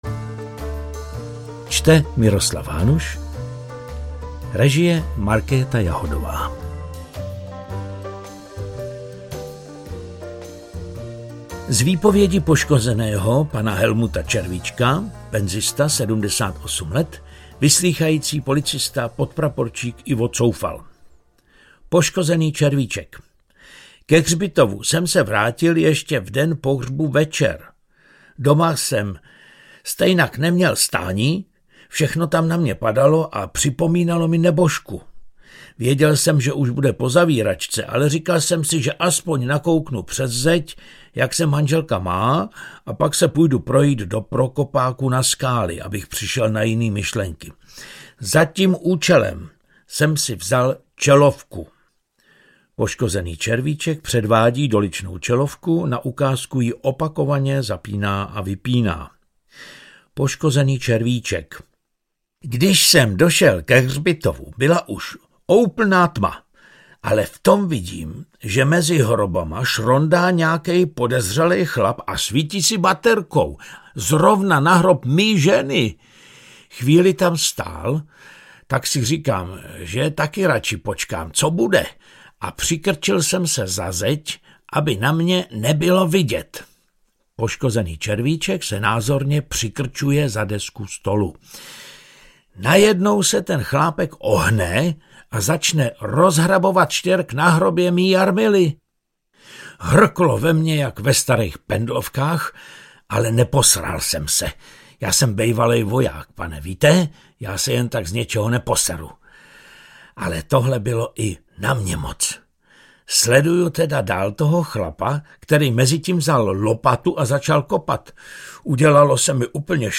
Bohumil audiokniha
Ukázka z knihy
• InterpretMiroslav Hanuš